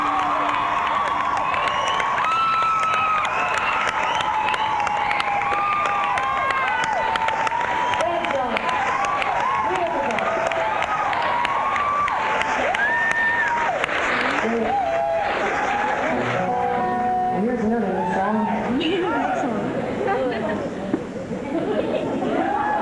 08. crowd (0:22)